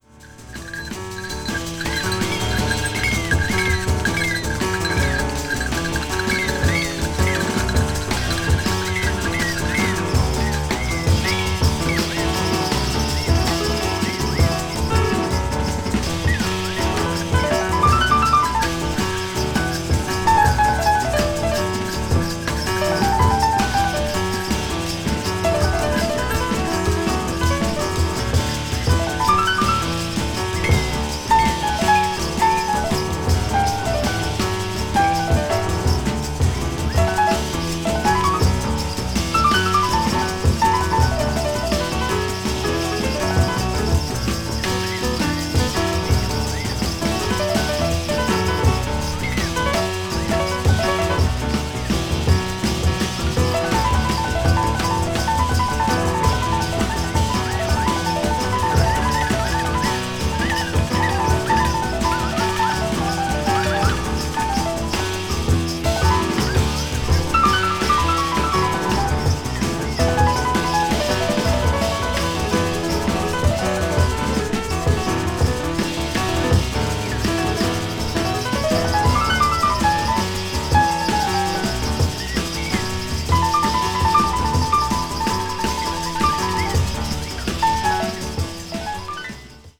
avant-jazz   contemporary jazz   ethnic jazz   free jazz